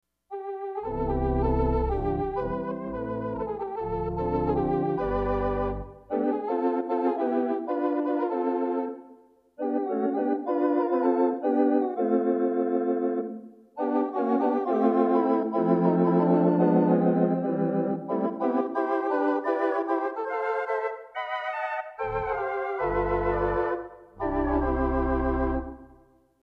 --Basic Tibia/Vox/String--
Tibia 8'
Viol d'Orchestre 8'
Vox Humana 8'
Piccolo 4'
Sort of the basic Tibia/Vox combination, with the addition of the String voice. It's a nice building block for your quiet ballad combinations.